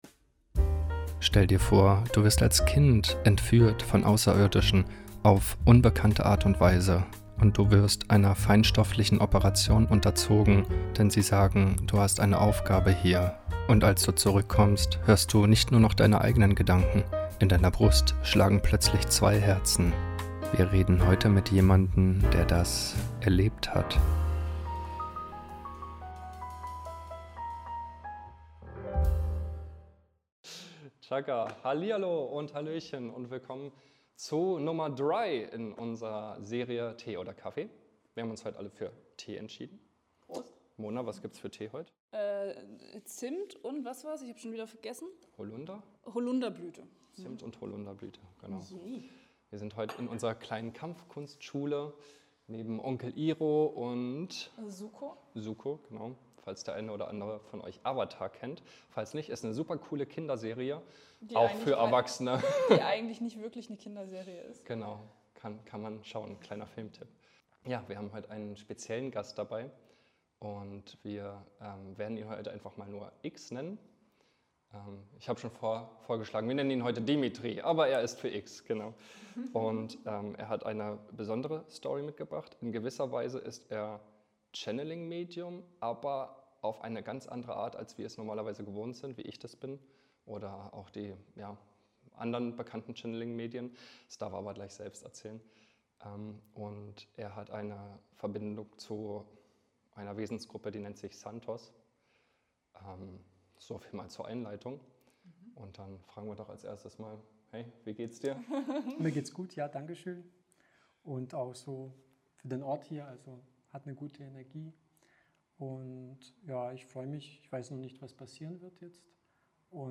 ☕ das spirituelle Interview Format Teil 1 / 3 Heute sprechen wir mit einem Medium, dass die Quelle SANTOS channelt.